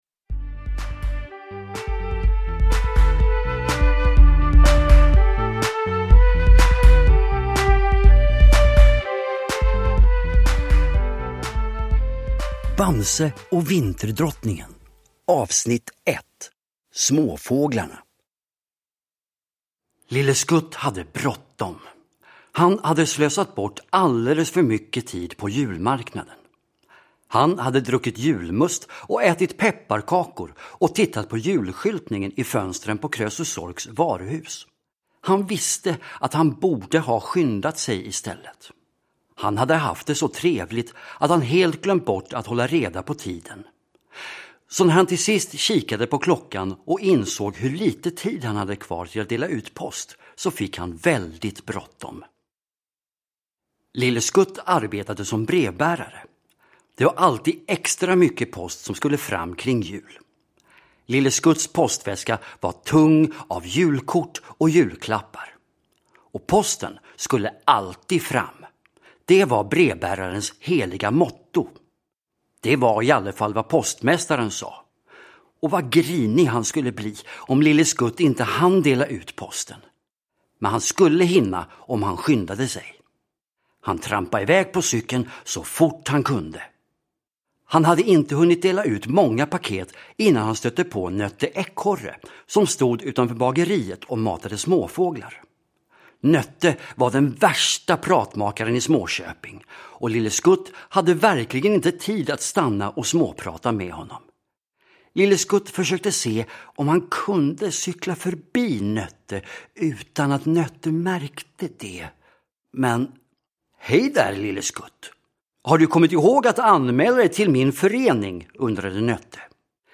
Bamse och Vinterdrottningen – Ljudbok – Laddas ner